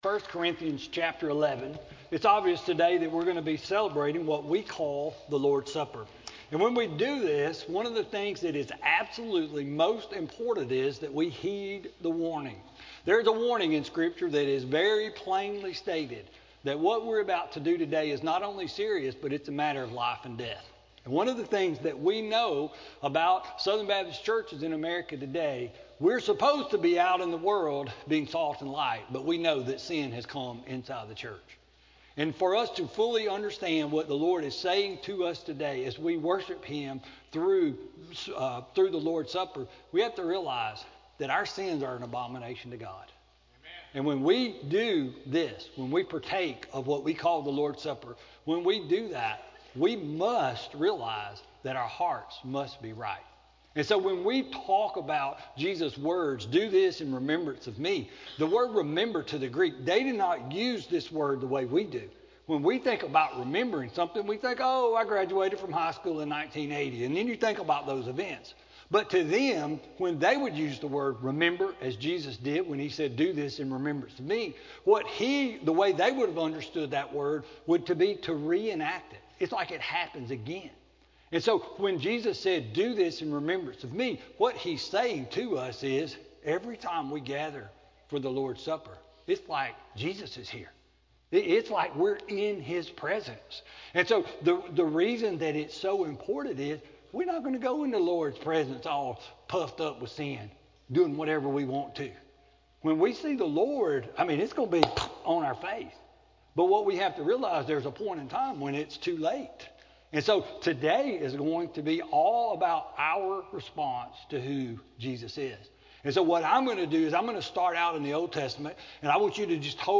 Lord’s Supper Service – March 31, 2019